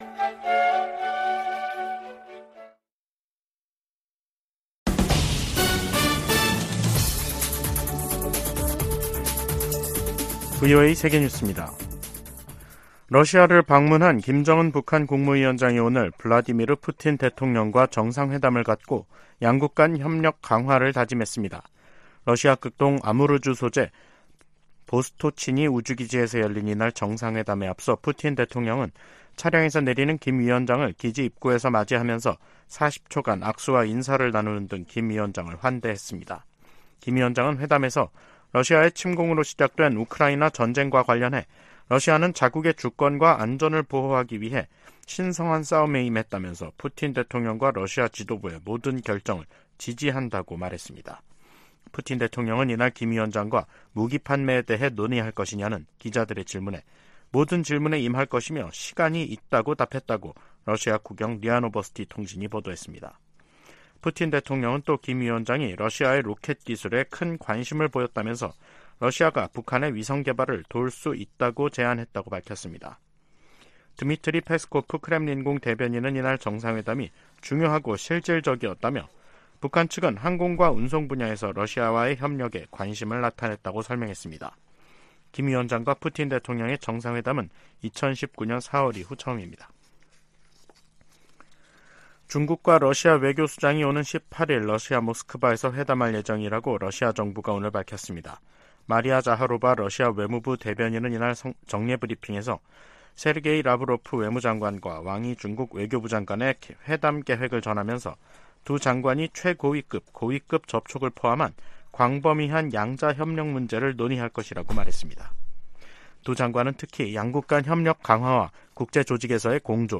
VOA 한국어 간판 뉴스 프로그램 '뉴스 투데이', 2023년 9월 13일 2부 방송입니다. 김정은 북한 국무위원장과 블라디미르 푸틴 러시아 대통령의 회담이 현지 시간 13일 오후 러시아 극동 우주기지에서 열렸습니다. 직전 북한은 단거리 탄도미사일 두 발을 동해상으로 발사했습니다. 미국 정부는 북한과 러시아의 무기거래에 거듭 우려를 나타내며 실제 거래가 이뤄지면 추가 행동에 나서겠다고 강조했습니다.